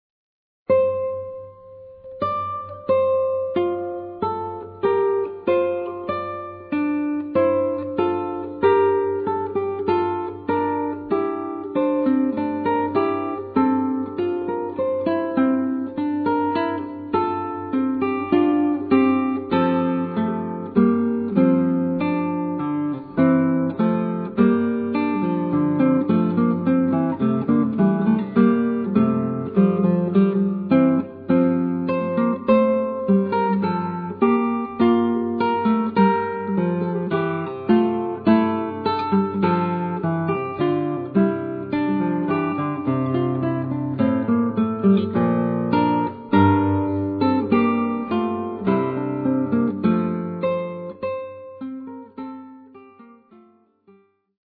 Classical
Solo guitar